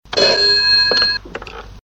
• Old Ringing Phone Sound WITH ECHO.mp3
Old Rotary telephone ringing in my grandmother's kitchen.
old_ringing_phone_sound_with_echo_fh8.wav